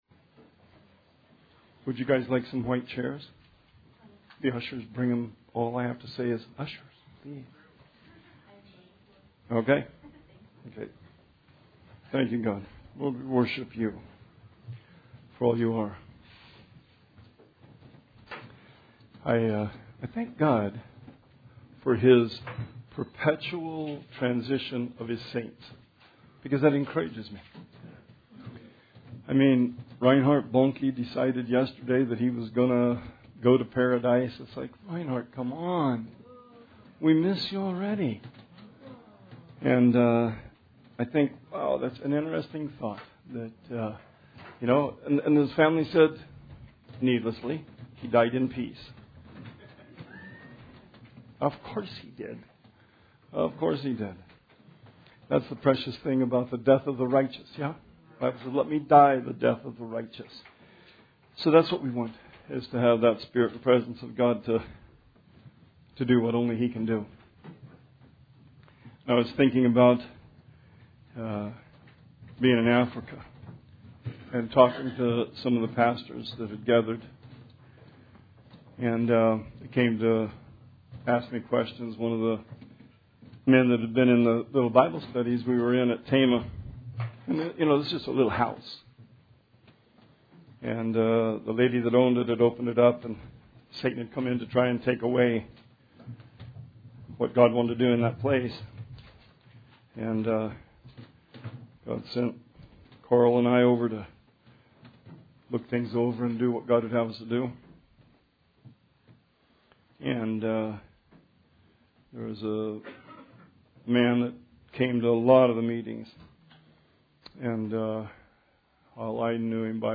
Sermon 12/8/19